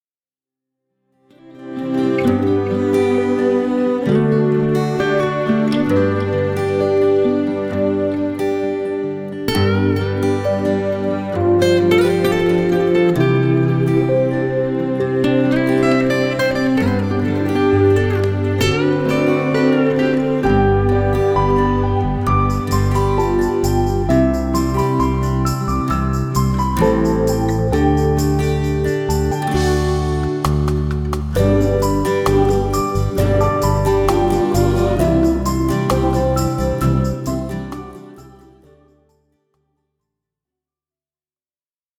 Play-Back